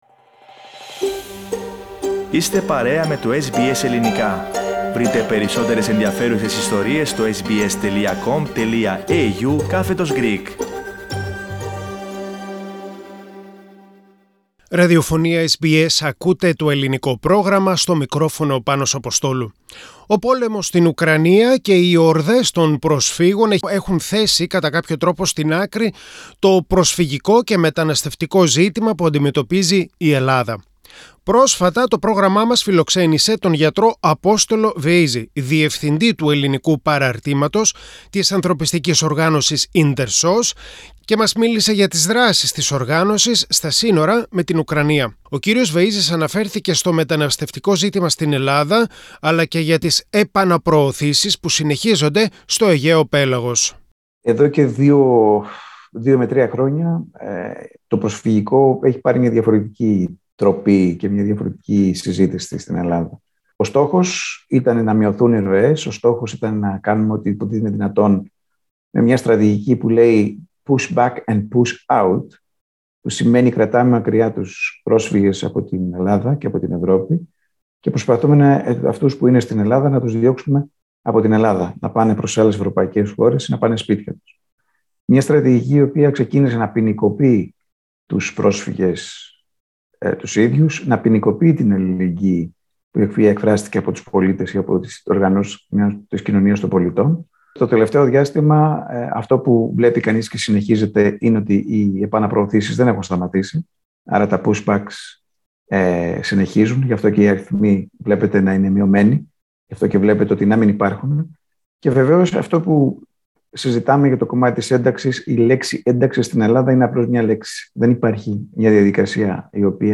Πατήστε Play στην κεντρική φωτογραφία για να ακούσετε την συνέντευξη με τον Δρ.